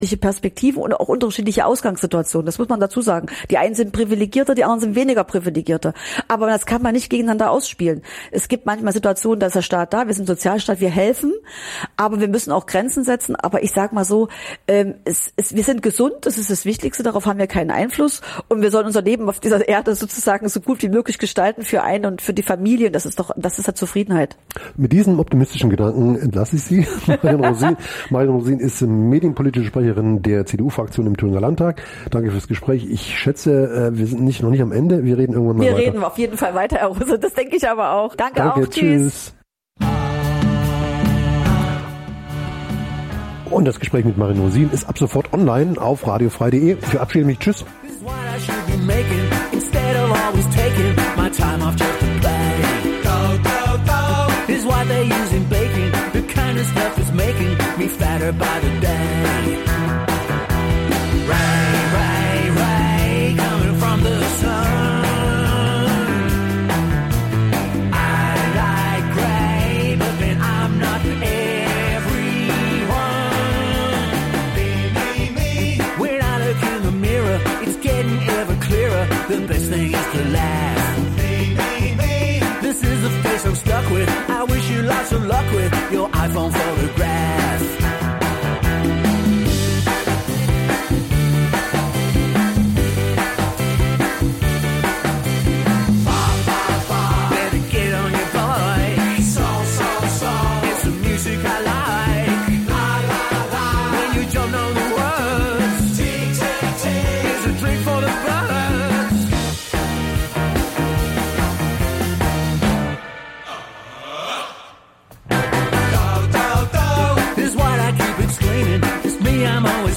Die Sendung f�r Downtempo & melodische langsame Technomusik. Macht euch bereit f�r eine Stunde sch�ne Kl�nge und Gef�hl der tanzbaren Art.
Musiksendung Dein Browser kann kein HTML5-Audio.